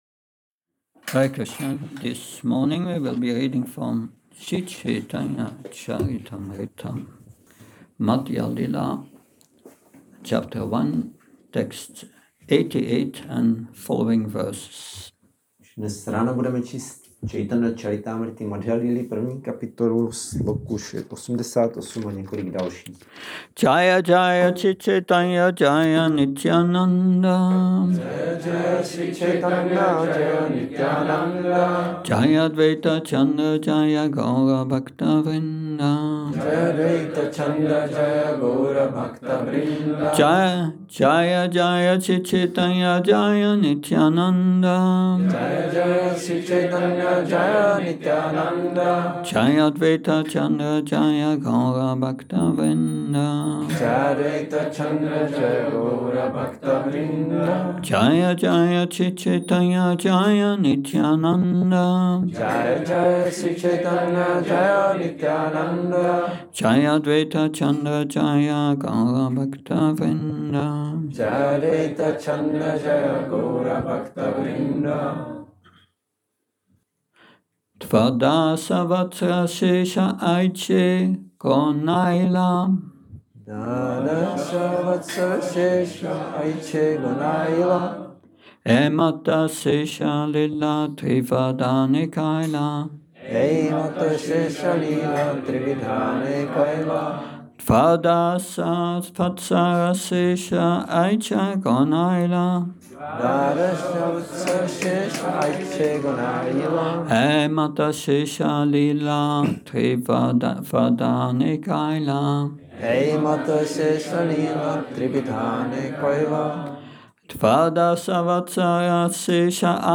Šrí Šrí Nitái Navadvípačandra mandir
Přednáška CC-MAD-1.88-95